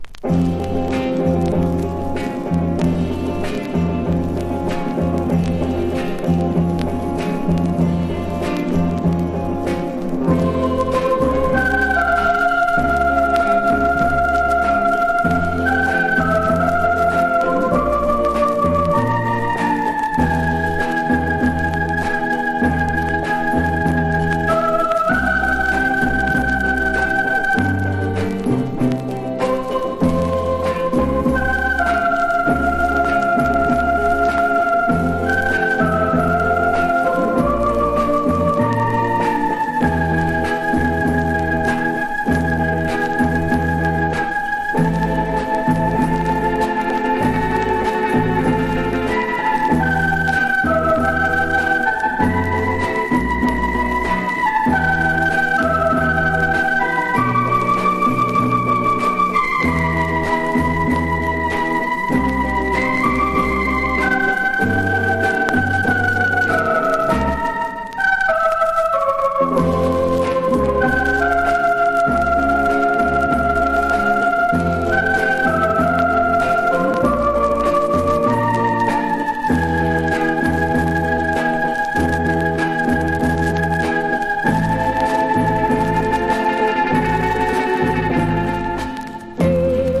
��007�٤Υ�����ɥե��󥬡��Υʥ������쥲������������!!��REGGAE